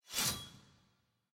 sfx-regalia-lobby-buildin-gold-split-3.ogg